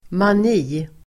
Uttal: [man'i:]